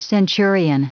Prononciation du mot centurion en anglais (fichier audio)
Prononciation du mot : centurion